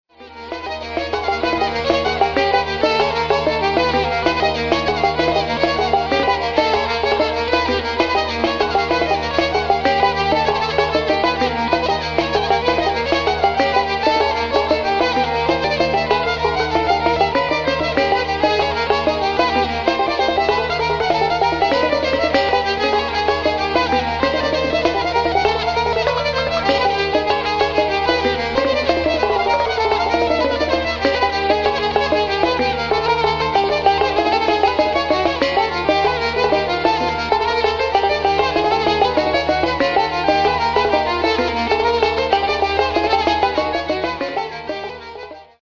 Re-discoverer of the hurdy-gurdy